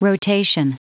Prononciation du mot rotation en anglais (fichier audio)